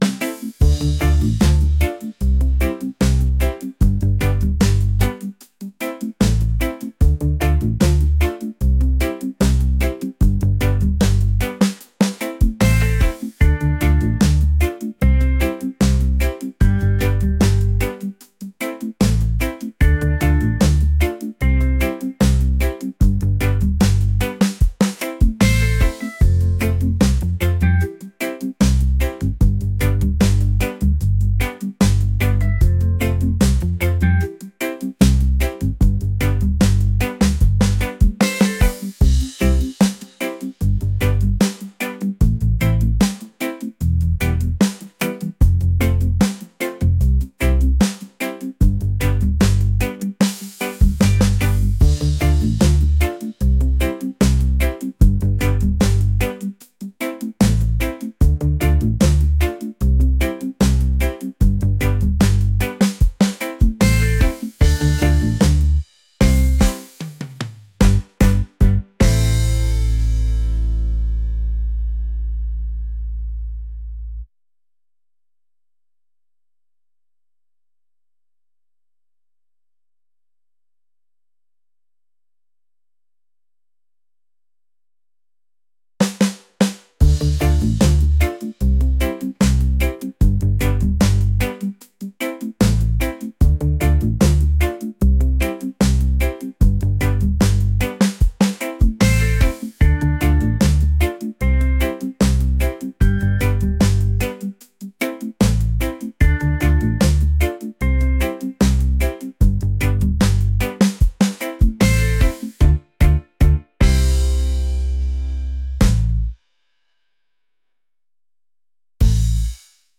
laid-back | reggae | groovy